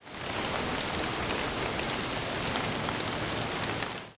rain1.ogg